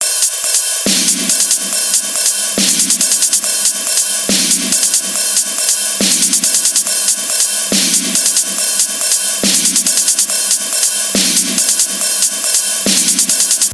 没有踢腿的Dubstep Drum Loop
描述：一个纯粹的hi hat和snare鼓循环，供你添加自己的kick。
Tag: 140 bpm Dubstep Loops Drum Loops 2.31 MB wav Key : Unknown